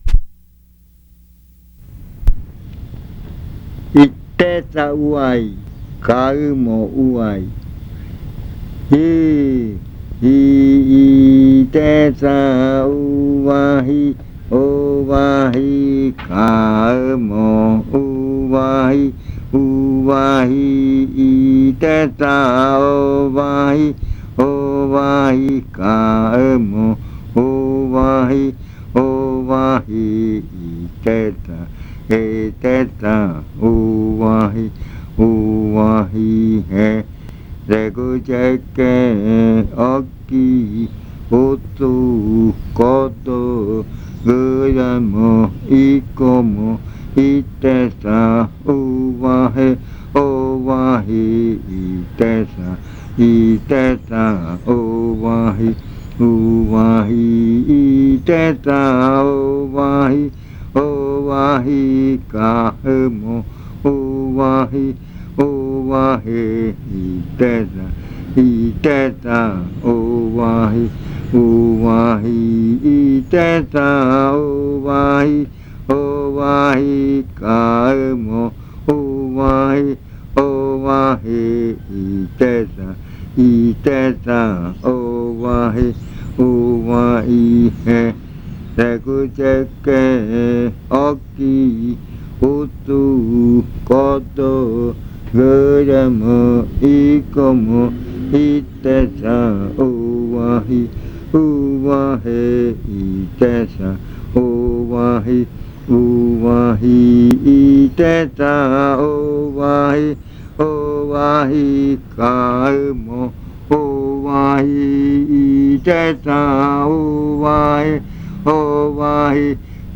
Leticia, Amazonas
Se canta en la tarde y por la noche. Canto con adivinanza.
Chant with a riddle.